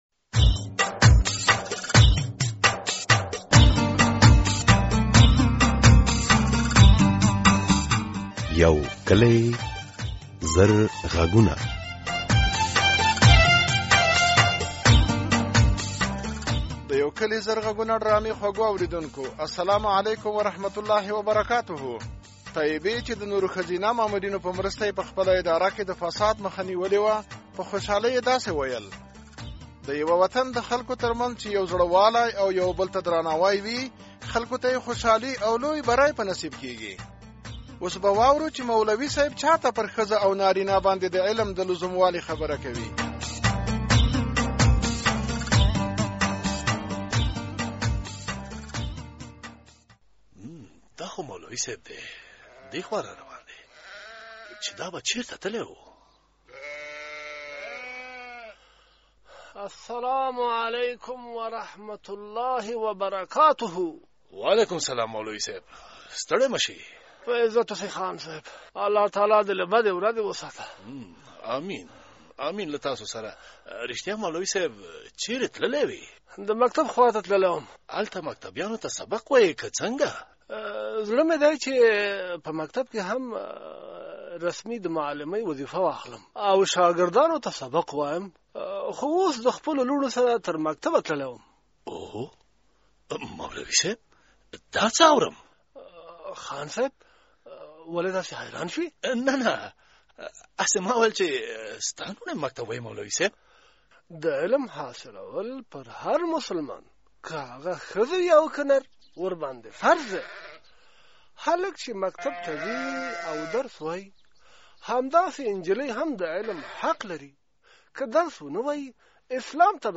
ډرامه